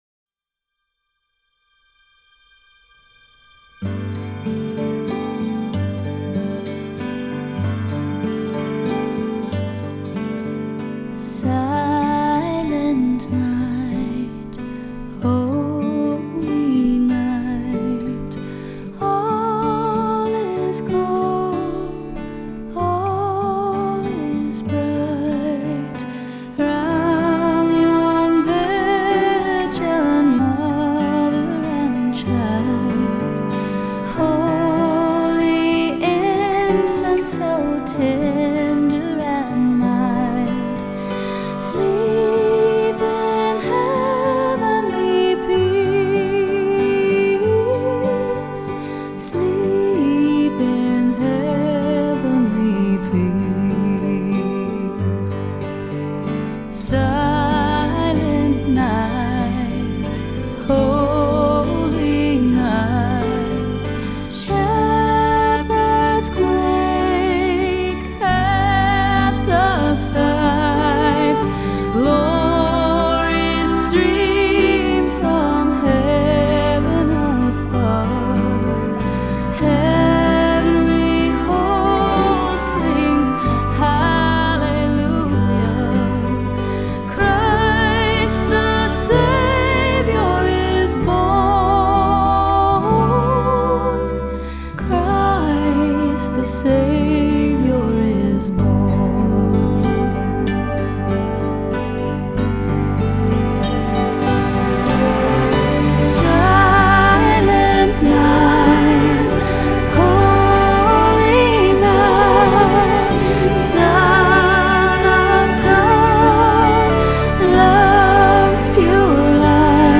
Holiday Music/Pop